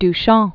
(d-shäɴ, dü), Marcel 1887-1968.